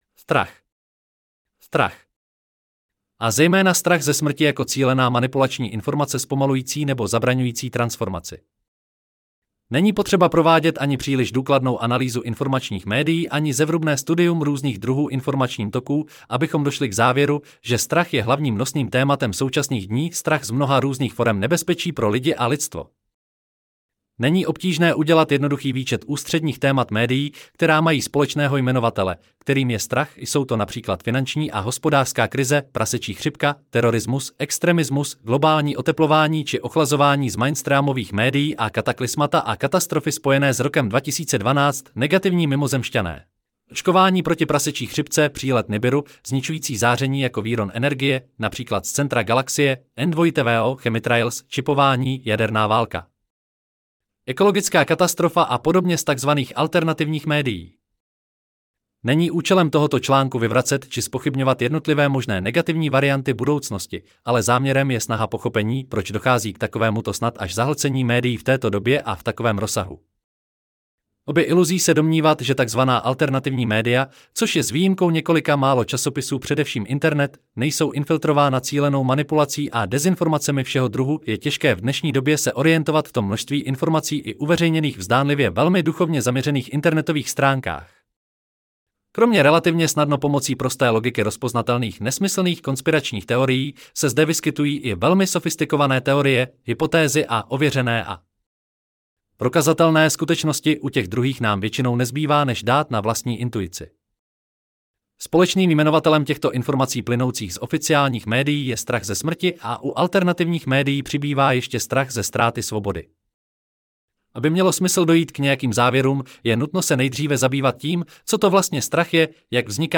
Strach Celý článek si můžete poslechnout v audioverzi zde: STRACH 9.10.2023 Strach a zejména strach ze smrti jako cílená manipulační informace zpomalující nebo zabraňující transformaci Není potřeba provádět ani příliš důkladnou analýzu informačních médií ani zevrubné studium různých druhů informačním toků, abychom došli k závěru, že strach je hlavním nosným tématem současných dní.